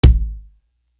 Bring You Light Kick.wav